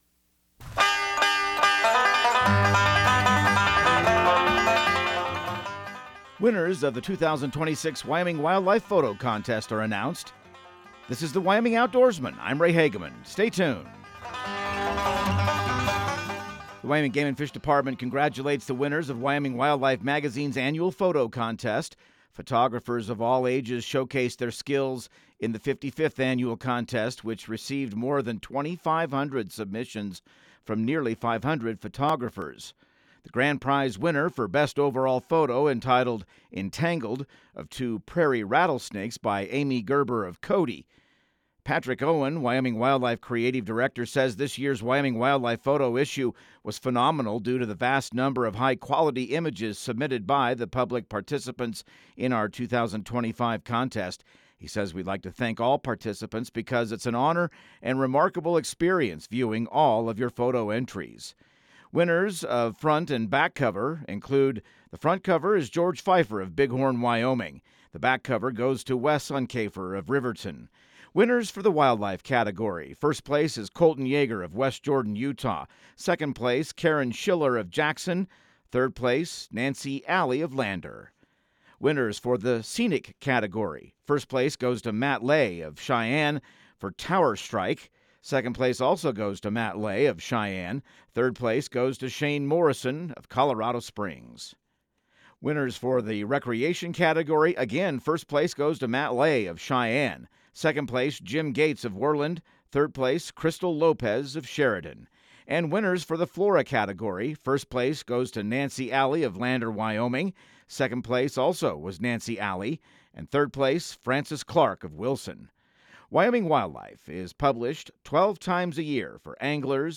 Radio news | Week of March 2